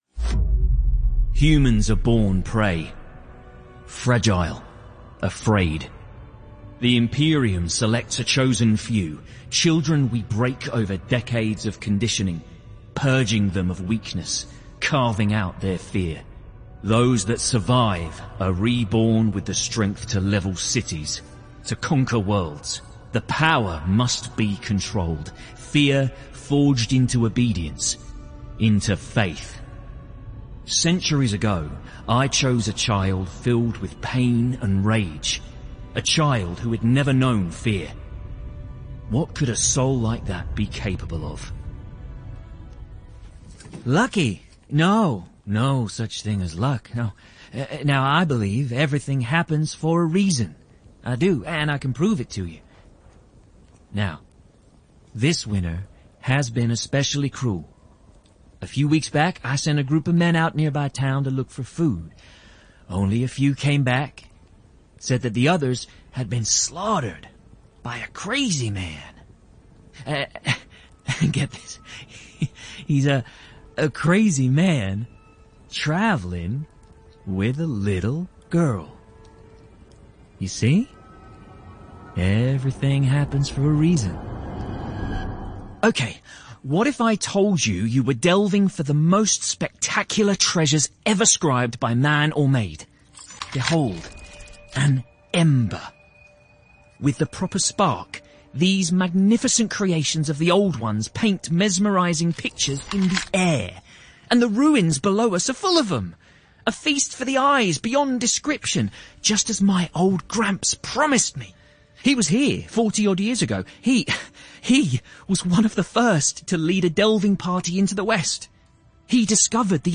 Cheery, intelligent, fun and clear
Video Game Reel